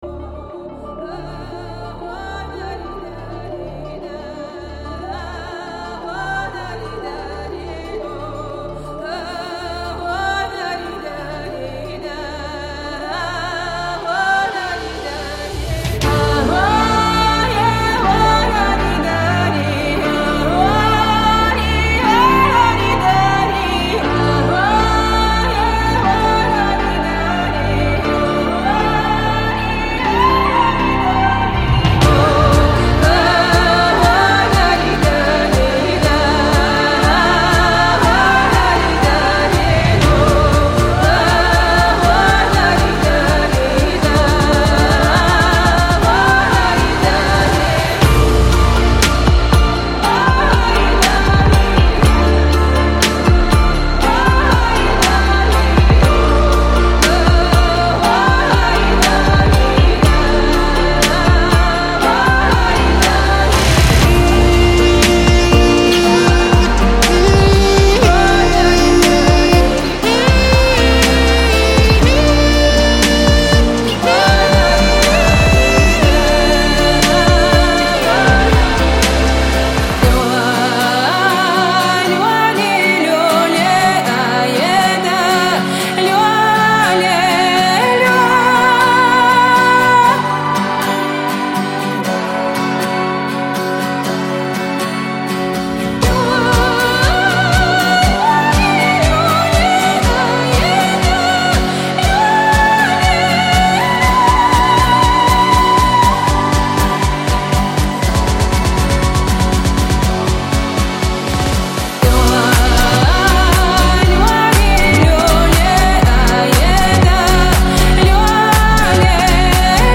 这个包装是来自不同大陆和不同时代的多元文化鸡尾酒，以现代性为背景，融合了过去的声音和乐器，
.120BPM
●164个人声循环
.22个Balalaika循环
.62个吉他循环